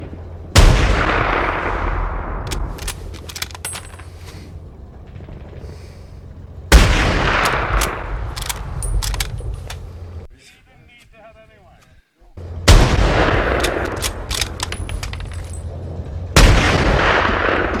Глухой звук выстрела снайпера в бою